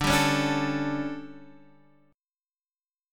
DM7sus4 chord